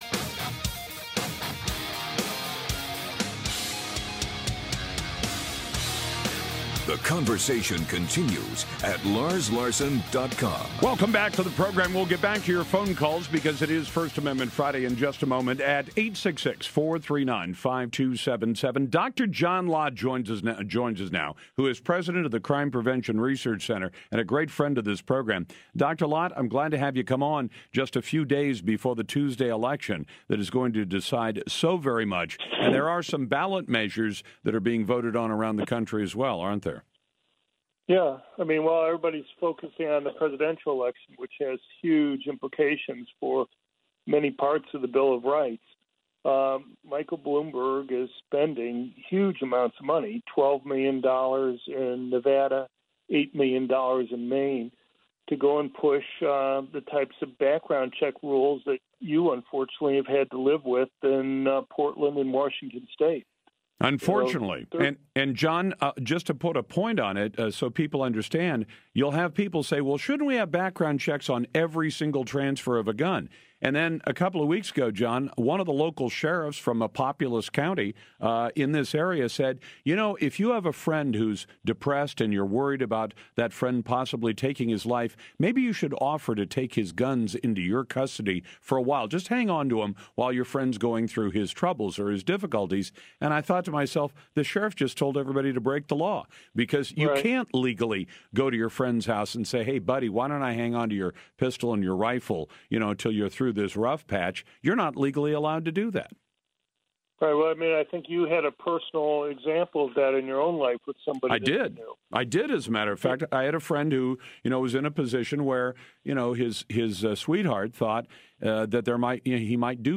Dr. John Lott talked to Lars Larson on his national radio show about Bloomberg’s push for his complicated regulations on background checks on private gun transfers.